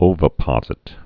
(ōvə-pŏzĭt)